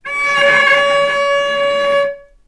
healing-soundscapes/Sound Banks/HSS_OP_Pack/Strings/cello/sul-ponticello/vc_sp-C#5-ff.AIF at 01ef1558cb71fd5ac0c09b723e26d76a8e1b755c
vc_sp-C#5-ff.AIF